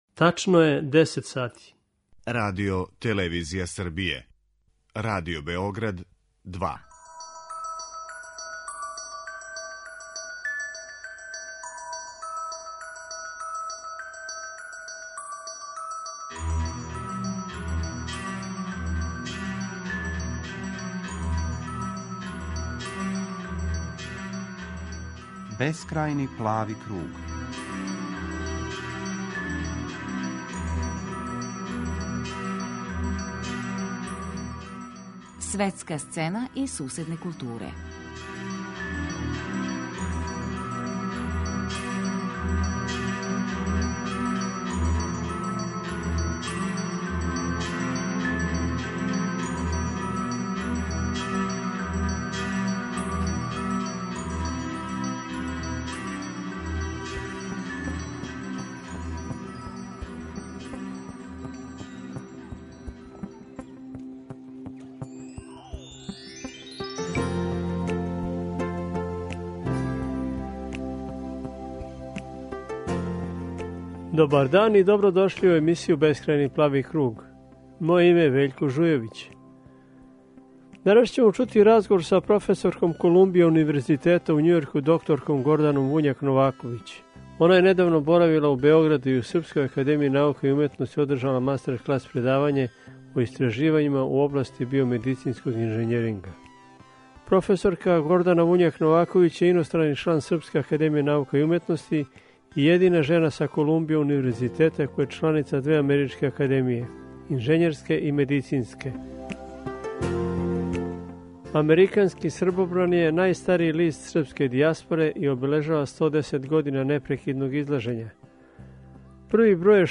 У данашњој емисији чућемо разговор са др Горданом Вуњак Новаковић, професорком Колумбија универзитета у Њујорку.